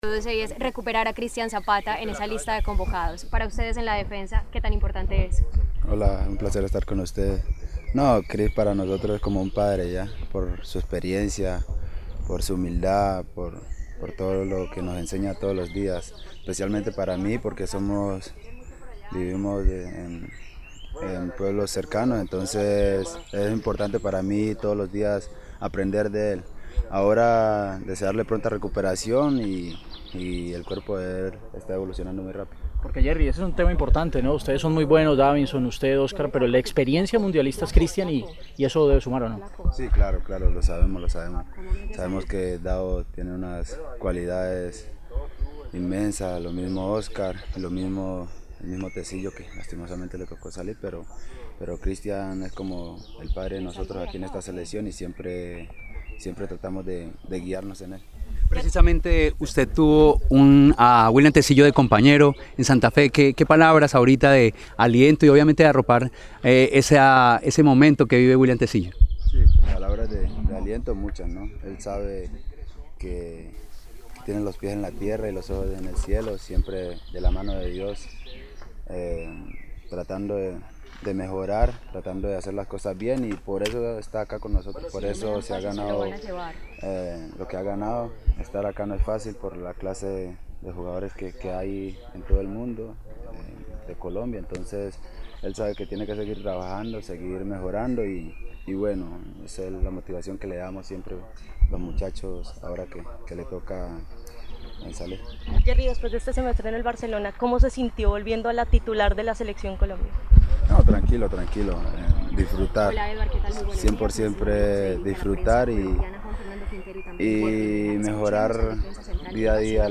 Antes del entrenamiento de la tarde, los jugadores Yerry Mina y Juan Fernando Quintero atendieron a la prensa para hablar del presente de la Selección Colombia, y la lista definitiva entregada por el entrenador.